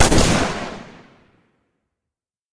描述：这是一个带有回声的M1加兰德爆炸的近距离立体声录音。
之后，我用滤波器、混响器、压缩器/限幅器等对录制的声音进行清理和改进。简而言之，这个声音并不是真正由我实地录制的，而是从YouTube视频中录制的。然而，我认为我对这个样本做了很好的处理（例如，我添加了一些立体声回声、冲击/爆炸声，并删除了一些难听的背景噪音）。
Tag: 步枪 二战 加兰德 武器 战争 射击